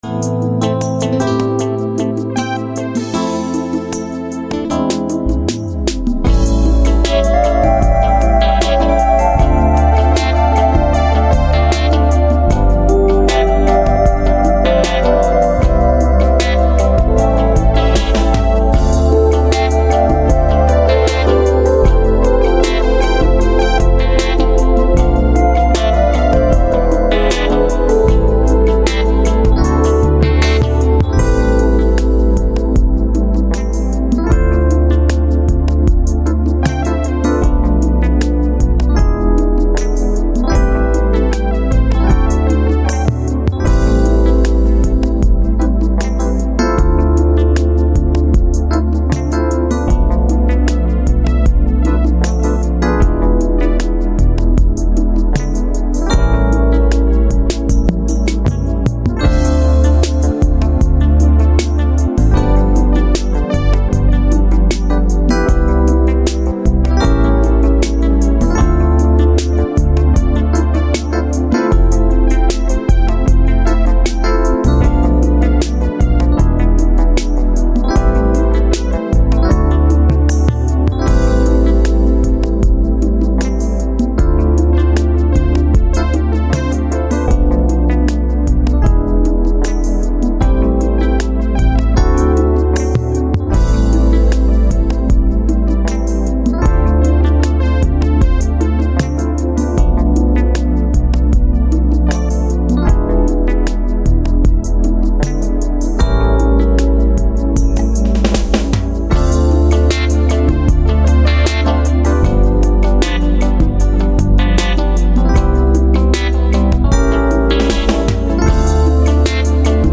минус песни